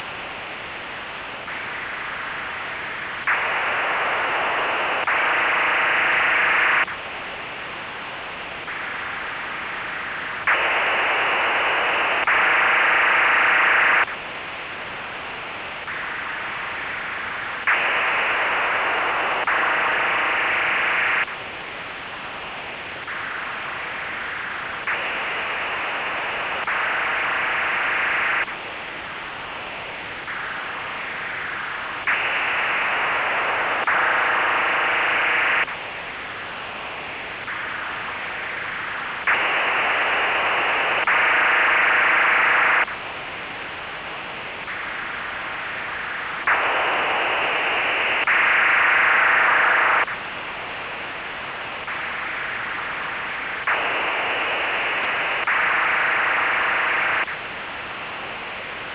Начало » Записи » Радиоcигналы на опознание и анализ
18213 kHz Br~2400 Hz PSK-4-8 ACF~37,5 ms